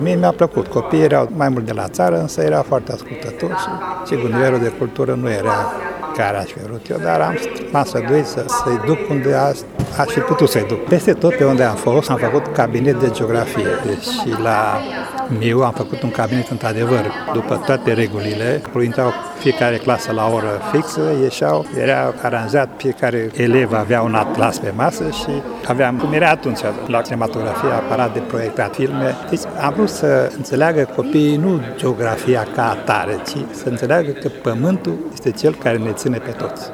Prima ediție a Galei Educației Mureșene a avut loc astăzi la Palatul Culturii și a fost dedicată dascălilor pensionari, care au primi diplome și medalii.